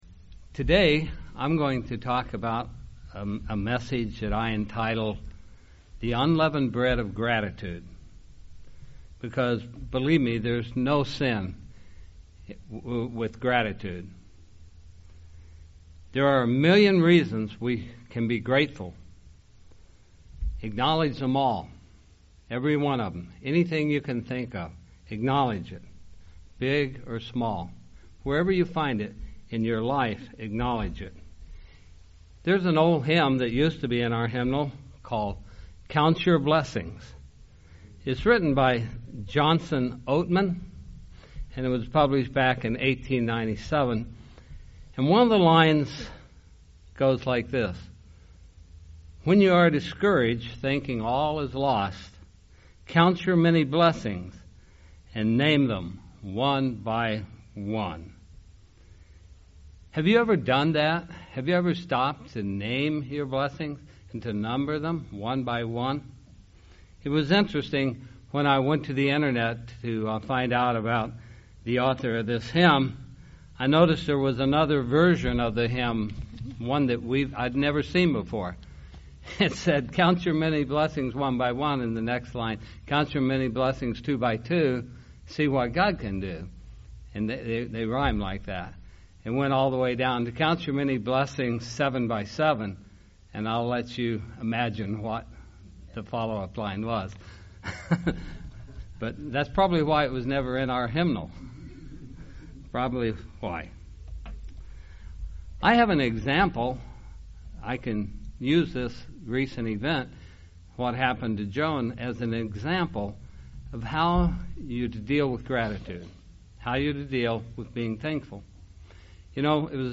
During this sermon, the speaker looks into the concept of gratitude and the role it should play in a Christian’s life.
Given in Springfield, MO
UCG Sermon Studying the bible?